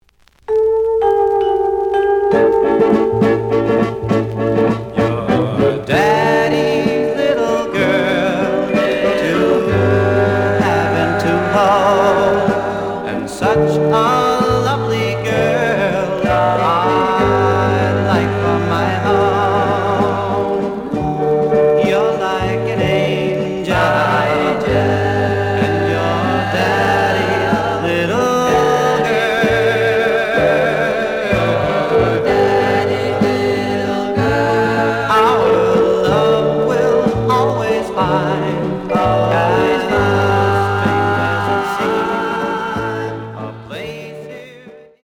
The audio sample is recorded from the actual item.
●Genre: Rhythm And Blues / Rock 'n' Roll
Slight edge warp. But doesn't affect playing. Plays good.)